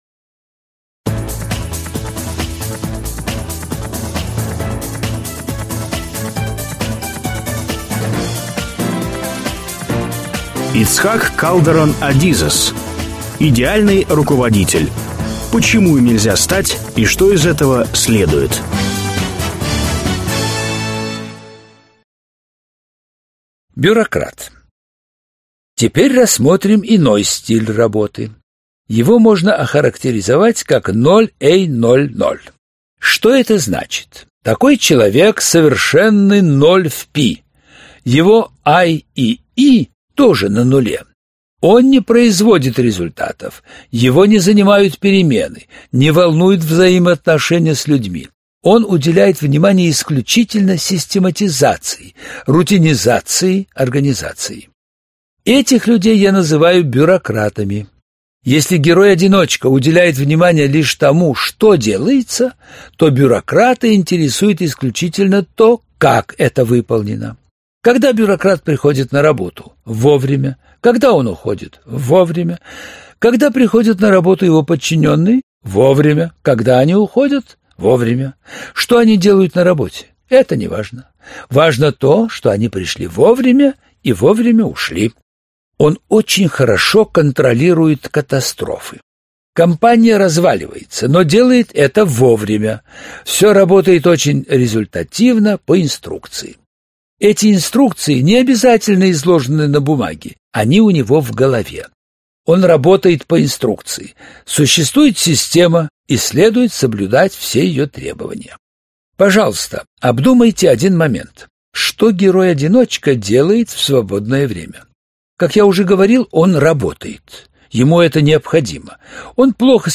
Аудиокнига Идеальный руководитель. Почему им нельзя стать и что из этого следует | Библиотека аудиокниг